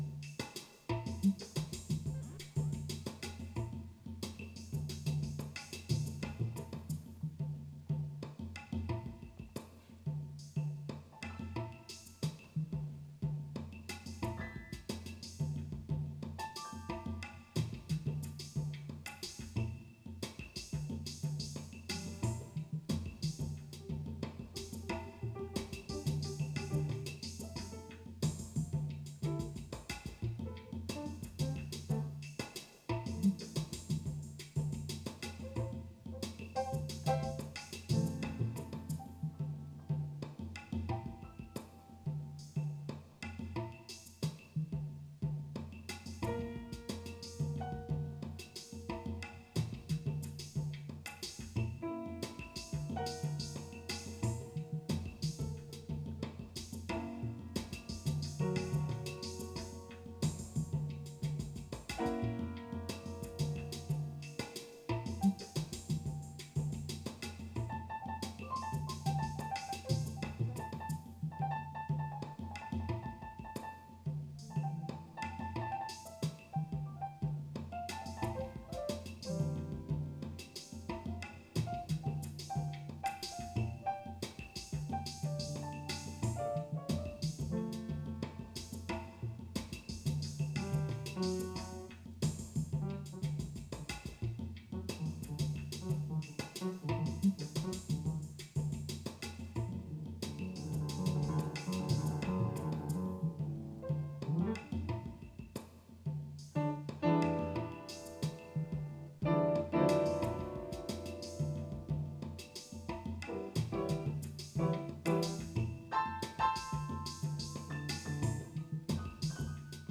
Piano improvisation written for Peanut Peace accompanies the installation recorded live @ the sound studio RCA -Piano and Roland Drum machine 2018